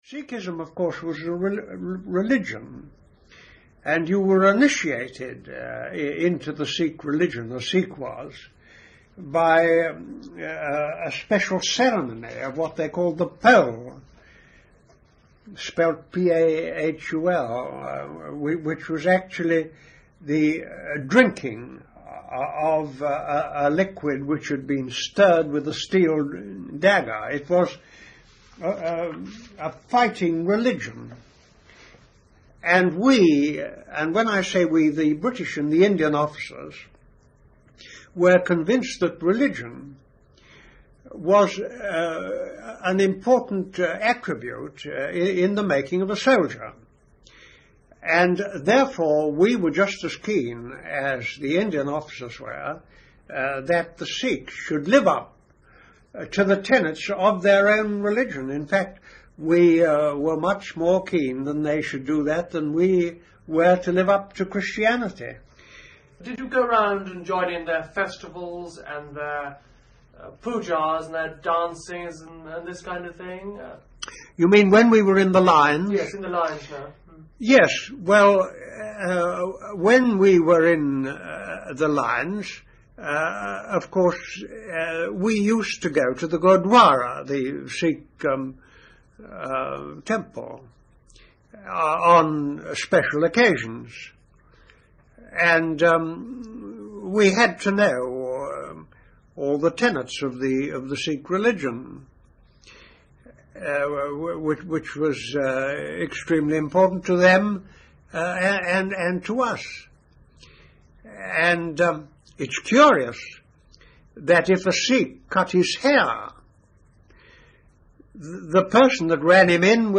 An in-depth interview with a British officer who won the Victoria Cross leading a near-suicidal mission on the Western Front with a group of ten Sikhs on 18 May 1915. His interview covers his joining the regiment, life in India, winning the Victoria Cross at the age of 22 and his relationship with the Sikhs.